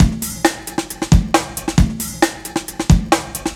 Funky Break 1 135.wav